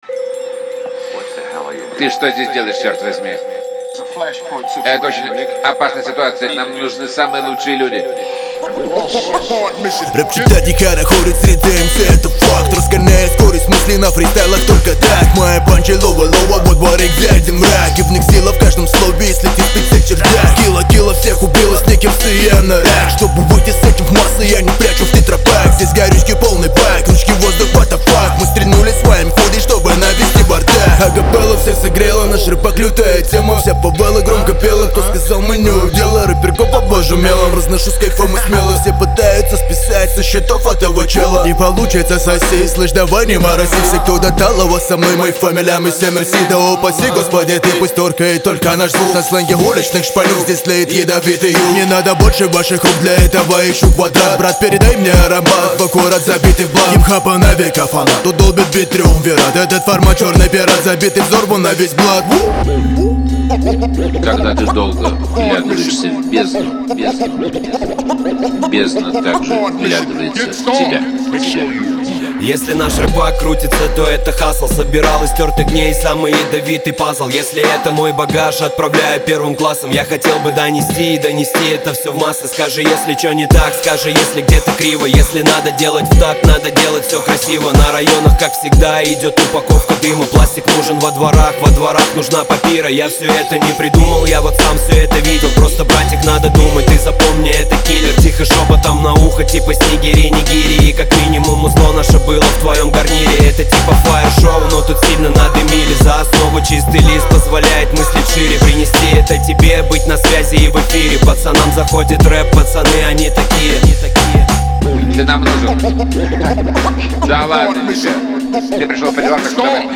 Жанр: rusrap